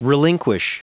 Prononciation du mot : relinquish
relinquish.wav